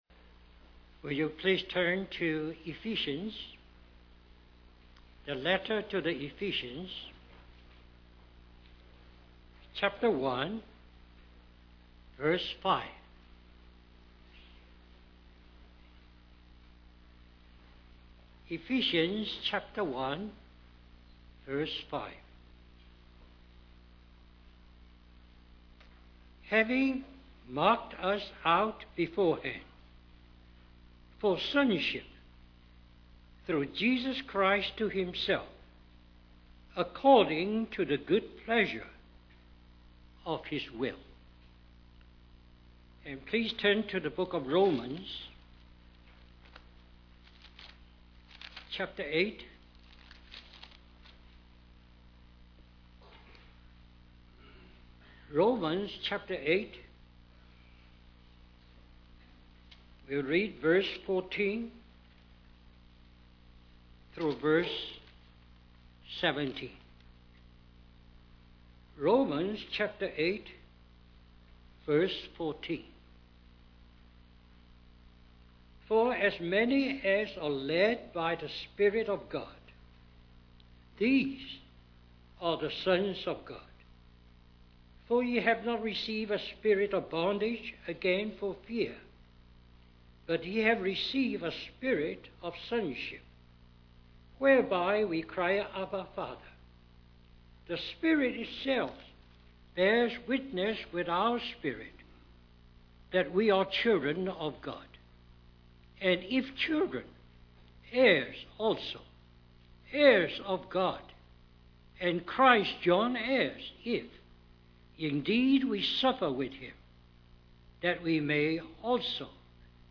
A collection of Christ focused messages published by the Christian Testimony Ministry in Richmond, VA.
Harvey Cedars Conference